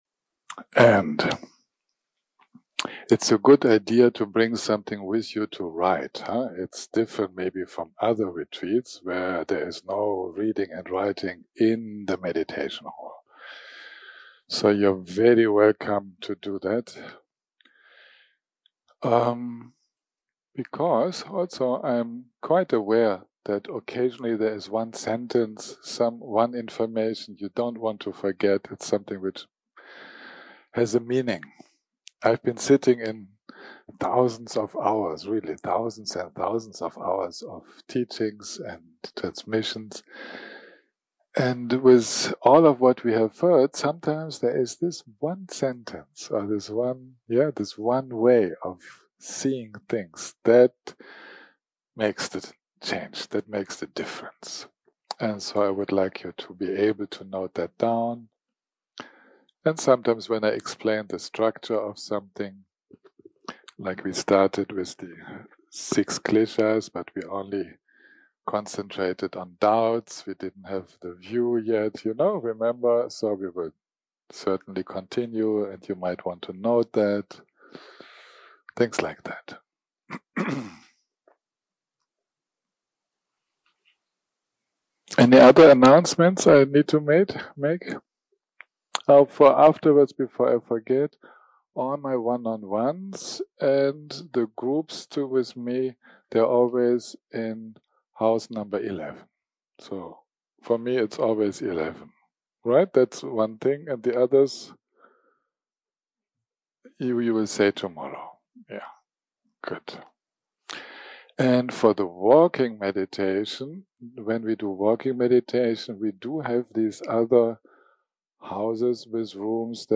day 2 - recording 4 - Afternoon - Meditation Guidance + Dharma talk
day 2 - recording 4 - Afternoon - Meditation Guidance + Dharma talk 0:00 0:00 סוג ההקלטה: Dharma type: Dharma Talks שפת ההקלטה: Dharma talk language: English